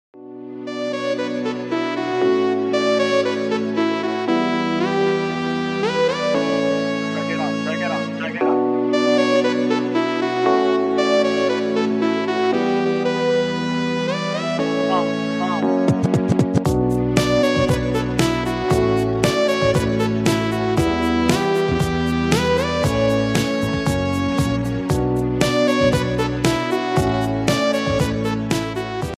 Telugu Ringtonelove ringtonemelody ringtoneromantic ringtone